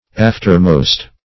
Aftermost \Aft"er*most\, a. superl.